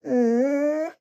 Minecraft Version Minecraft Version snapshot Latest Release | Latest Snapshot snapshot / assets / minecraft / sounds / mob / wolf / puglin / whine.ogg Compare With Compare With Latest Release | Latest Snapshot
whine.ogg